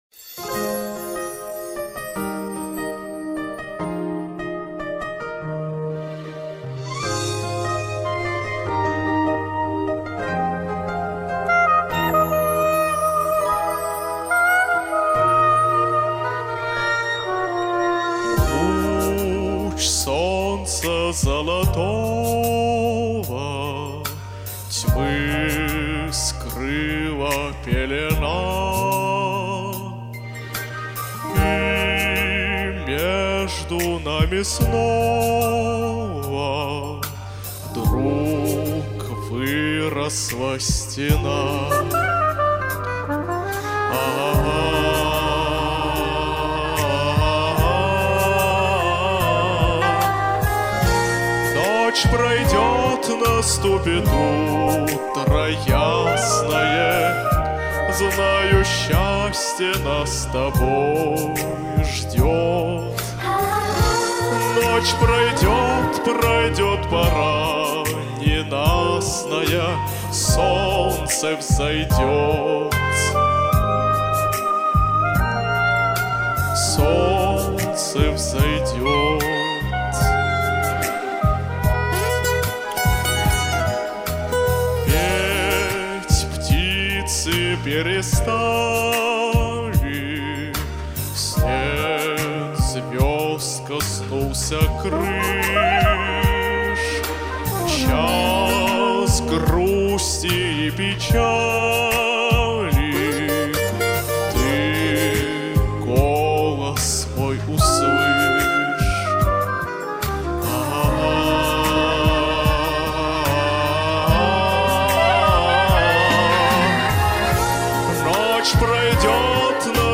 звучит чрезмерно правильно... но голос очень красивый